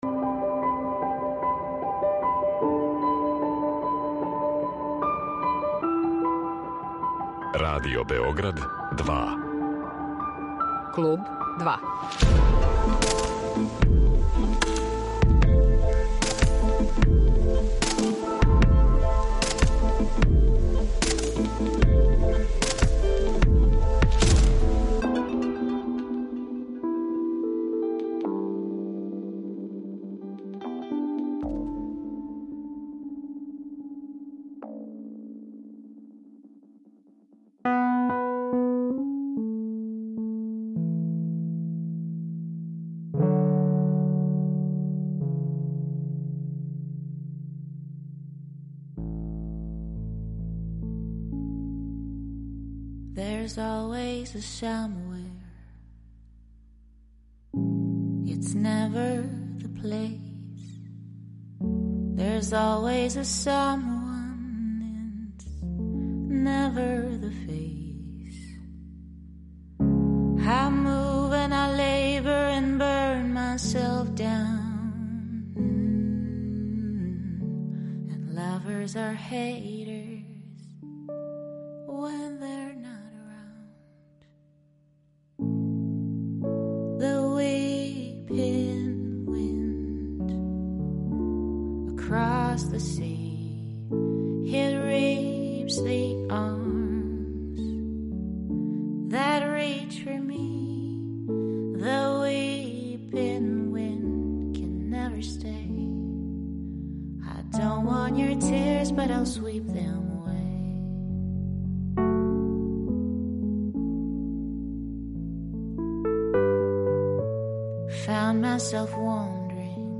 Певачица, пијанисткиња и композиторка
Наравно, слушаћемо неке нове и нешто старије песме.